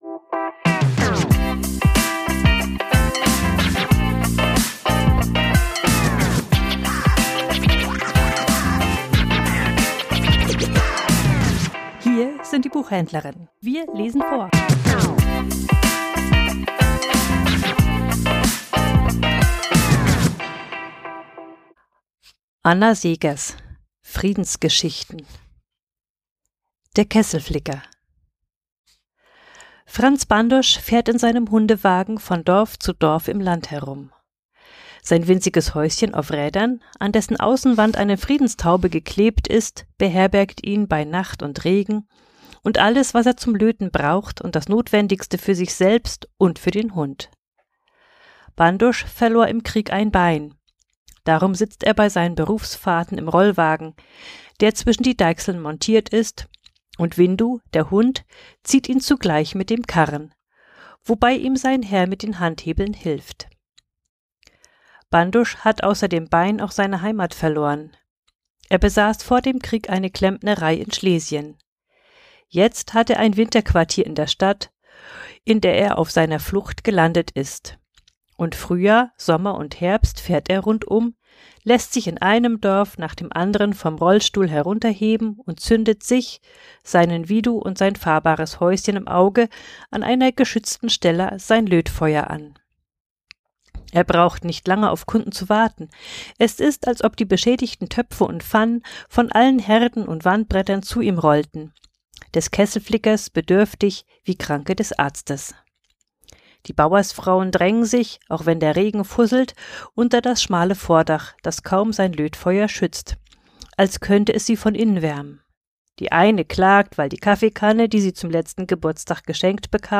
Vorgelesen: Der Kesselflicker ~ Die Buchhändlerinnen Podcast